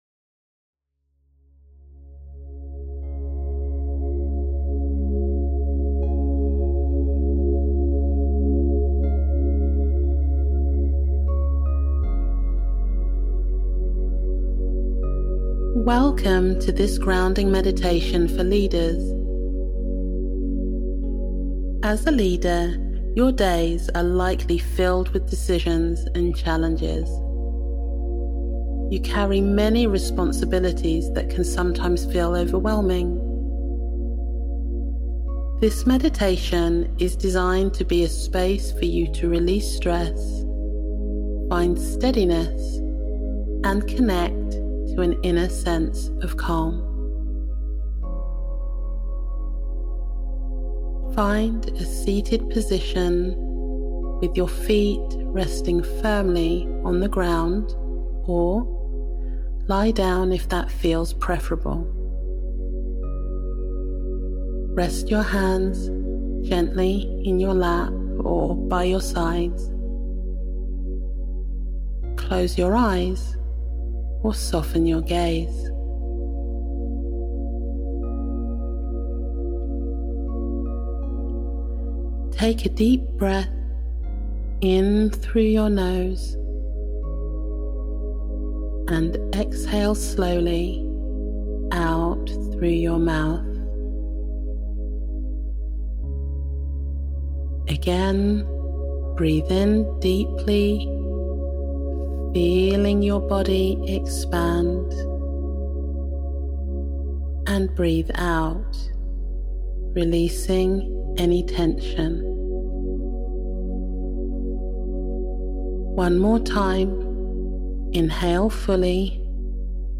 Meditation
Relaxation-Meditation-for-Leaders.mp3